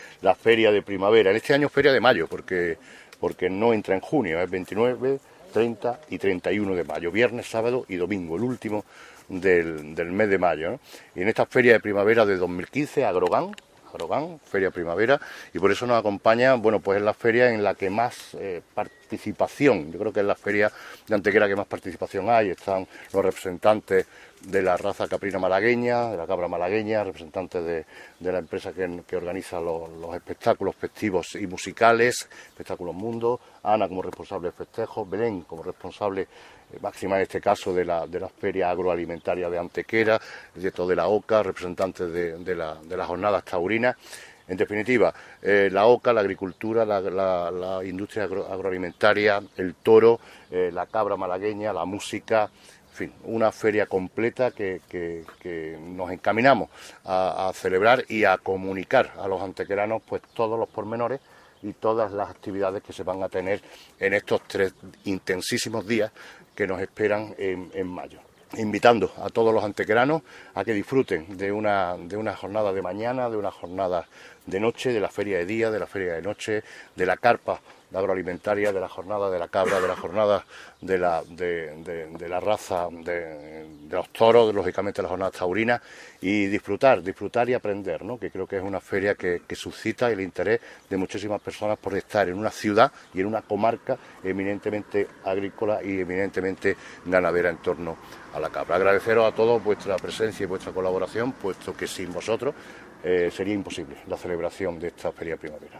Cortes de voz
M. Barón   725.25 kb  Formato:  mp3